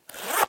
Звуки ширинки